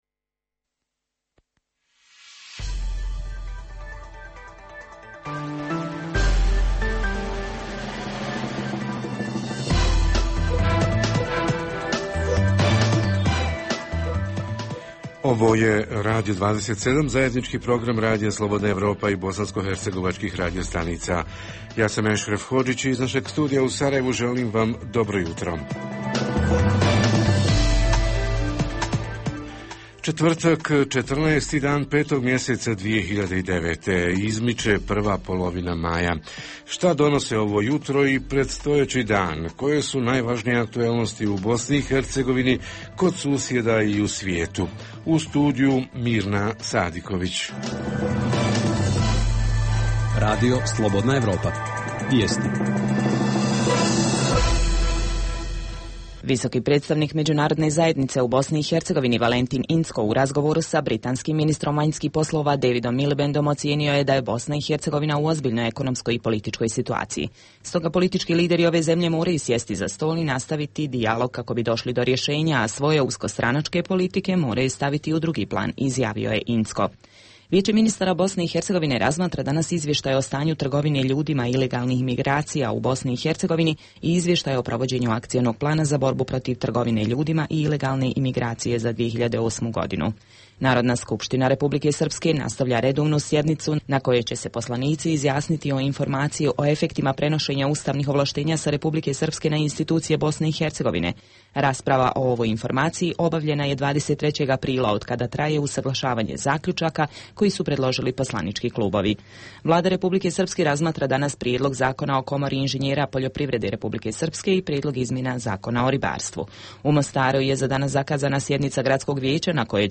Jutarnji program za BiH koji se emituje uživo. Tema jutra: bankarske usluge i njihove cijene u uvjetima ekonomske krize Reporteri iz cijele BiH javljaju o najaktuelnijim događajima u njihovim sredinama.
Redovni sadržaji jutarnjeg programa za BiH su i vijesti i muzika.